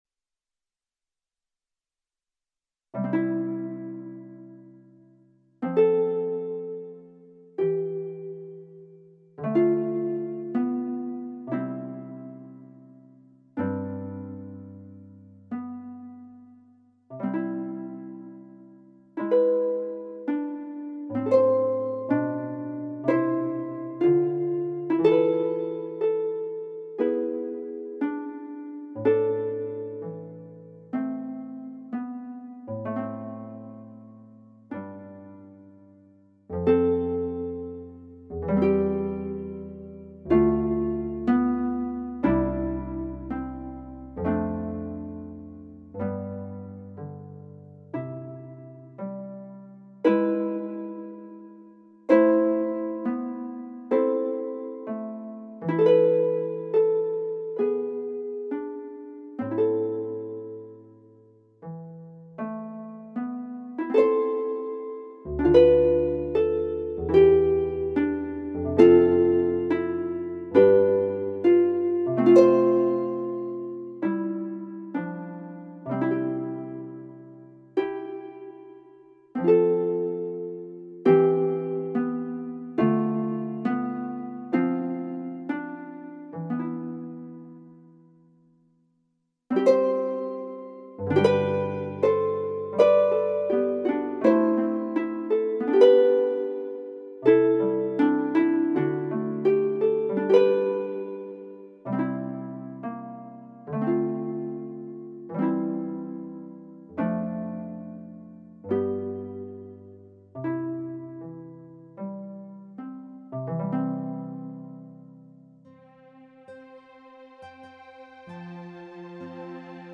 The first project is simply instrumental Catholic/Christian standards as music for meditation to use during Lent and Holy Week.